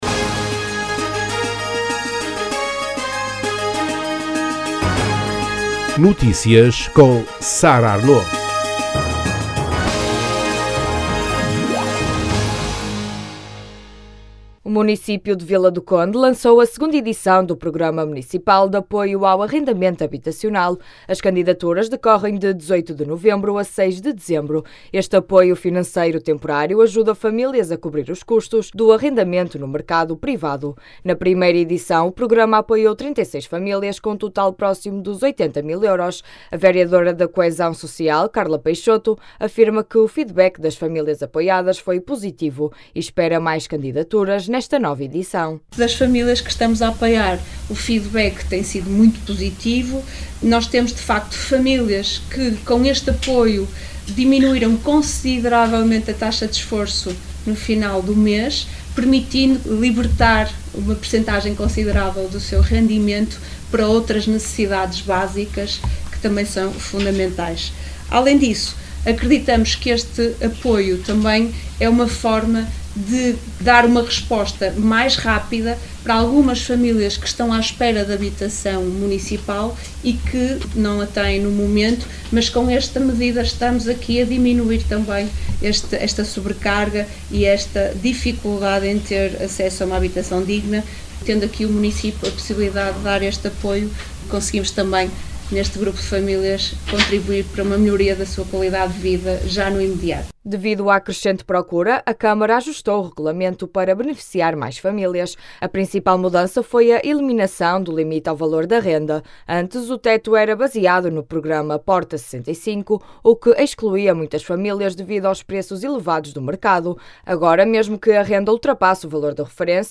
A vereadora da coesão social, Carla Peixoto, afirma que o feedback das famílias apoiadas foi positivo e espera mais candidaturas nesta nova edição.